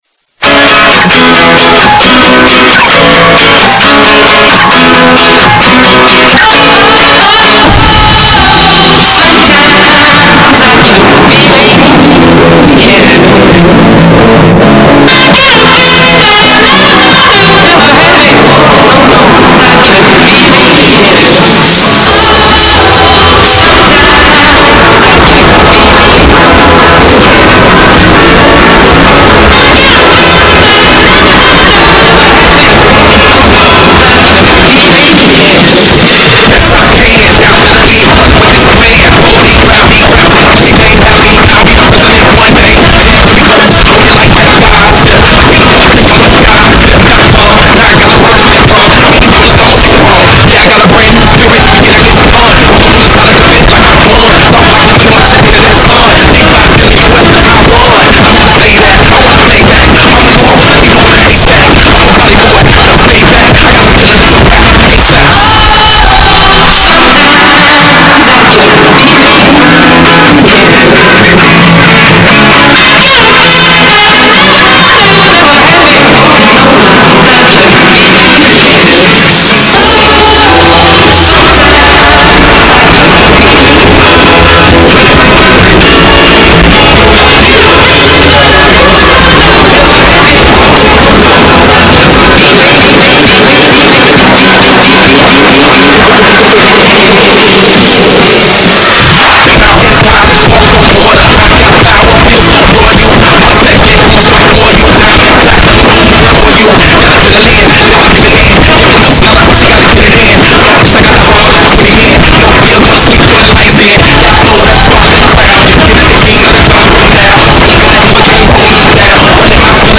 hip rap.